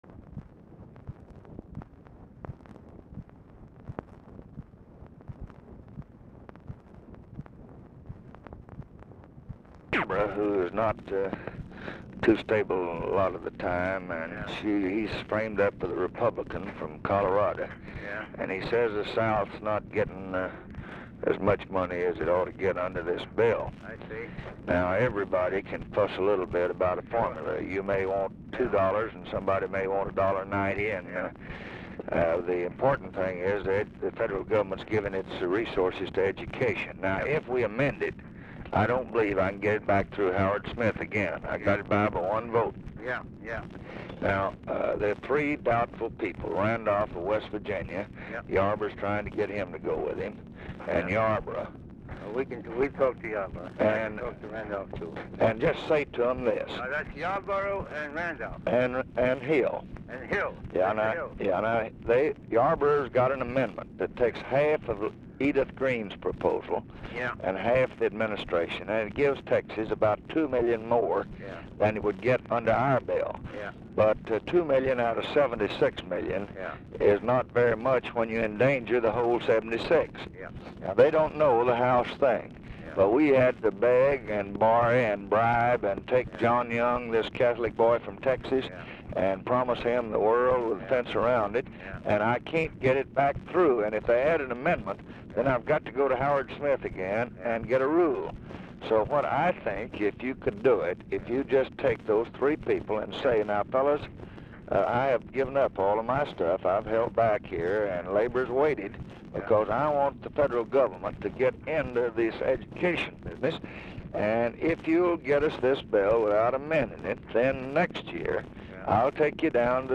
Telephone conversation # 7301, sound recording, LBJ and GEORGE MEANY, 4/1/1965, 9:00AM | Discover LBJ
RECORDING STARTS AFTER CONVERSATION HAS BEGUN
Format Dictation belt
Location Of Speaker 1 Mansion, White House, Washington, DC